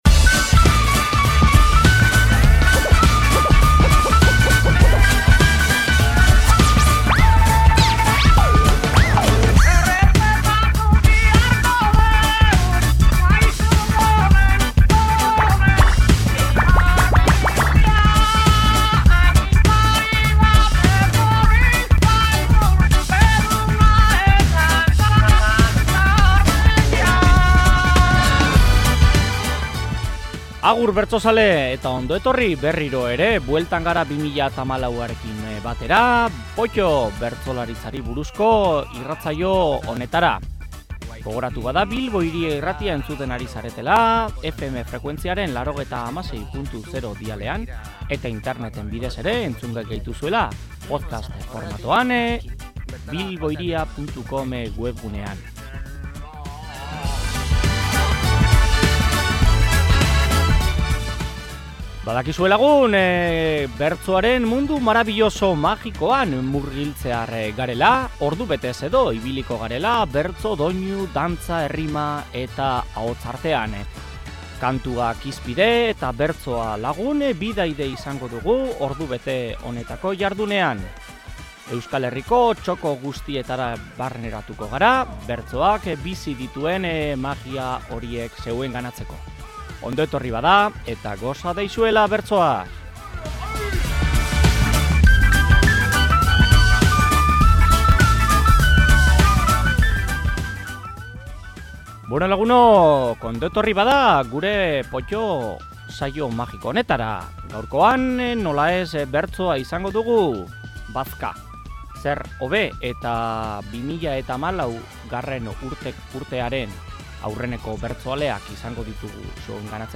Hasteko, Zarautzko Putzuzuloko Kopla Txapelketa entzuteko parada izan dugu.
DESKARGATU HEMEN Posted in Entzungai (podcast) , Potto Tagged # bertsolaritza # Potto